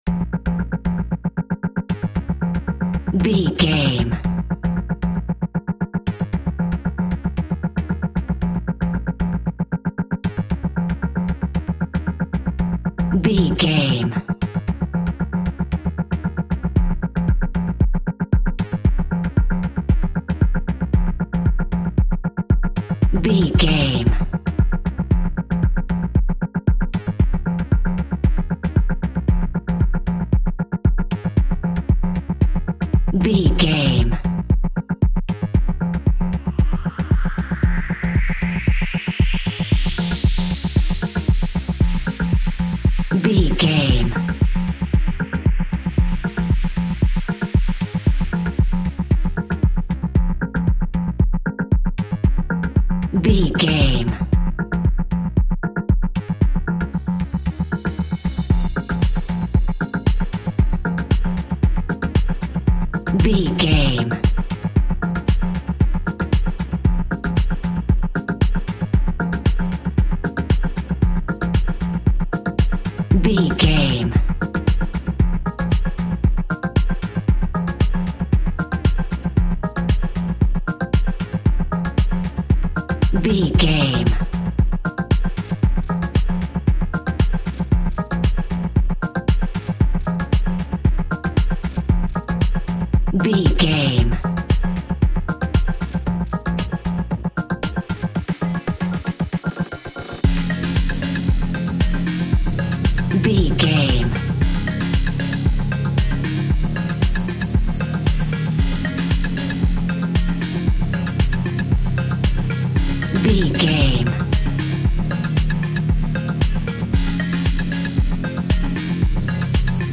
Aeolian/Minor
ethereal
dreamy
cheerful/happy
groovy
synthesiser
drum machine
house
electro dance
techno
trance
synth leads
synth bass
upbeat